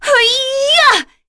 Seria-Vox_Casting3.wav